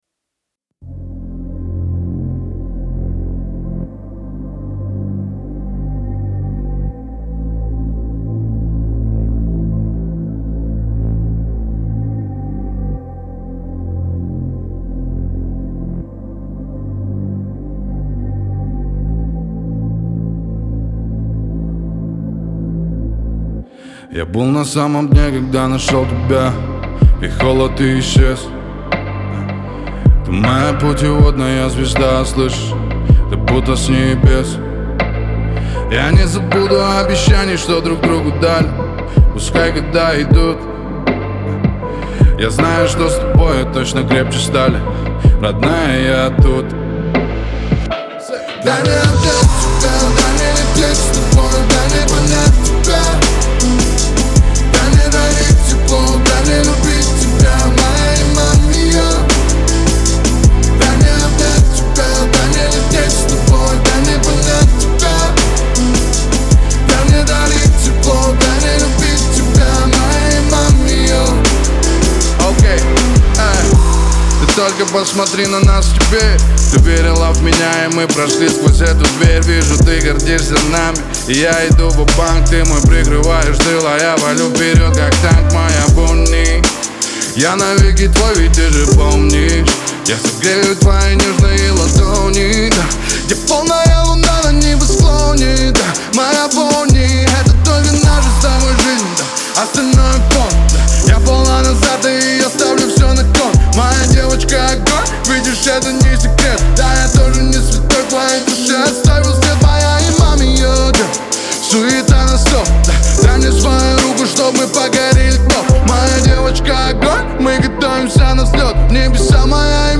хип-хоп и R&B
выразительный вокал и запоминающиеся мелодии